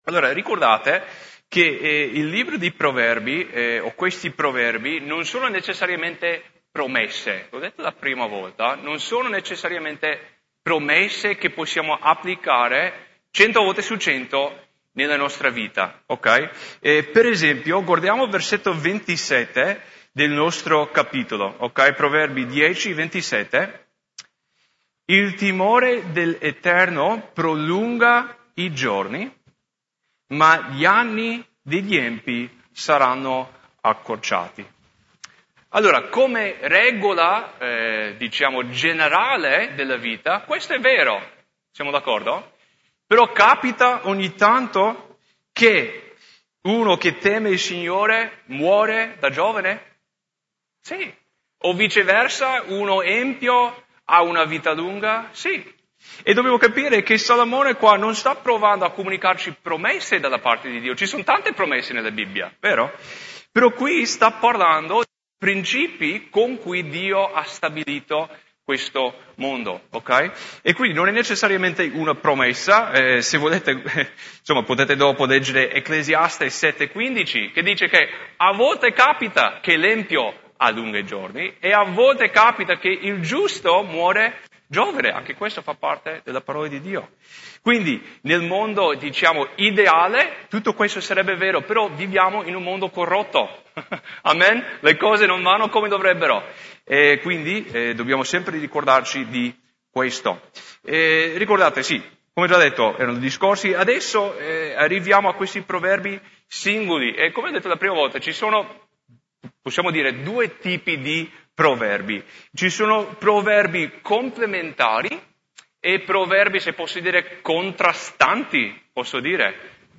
Studio biblico di Mercoledì 24 Luglio